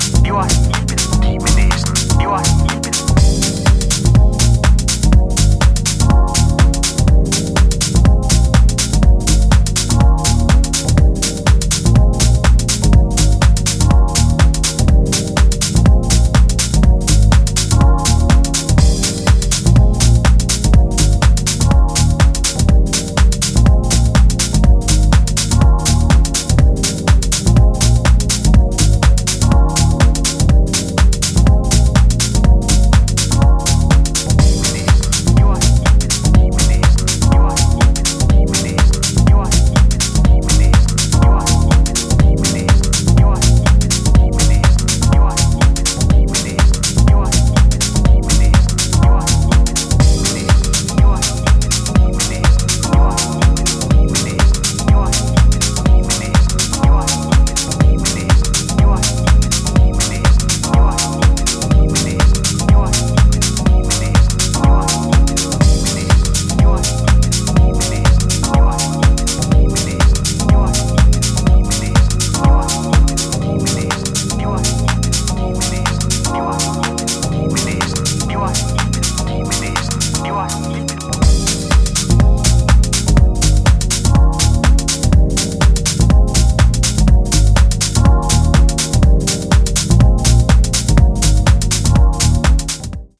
offers a deep and soulful groove